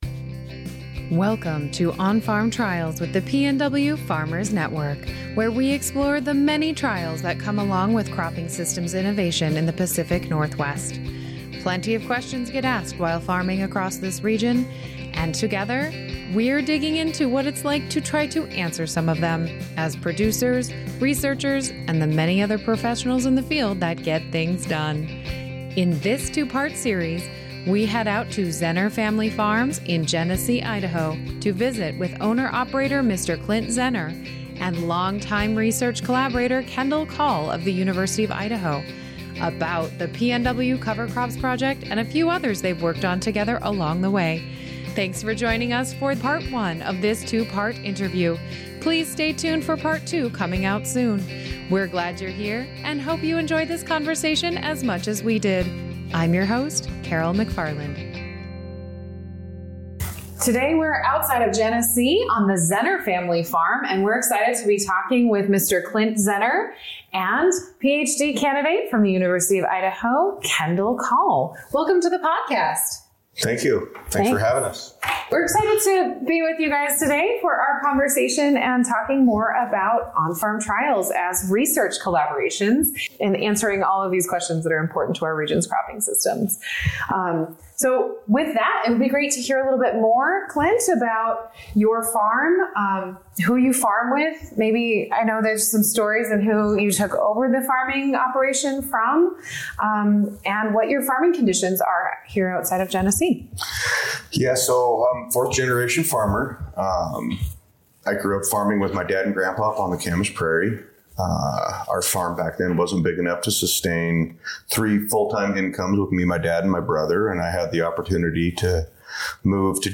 A conversation with them highlights years of working together that has resulted in a wealth of knowledge on cover crops, cows, and even earth worms! Listen to their conversation about how they are working together to ask and answer important soil health and cropping systems innovation for the farm and region with their on-farm trials.